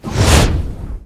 fire3.wav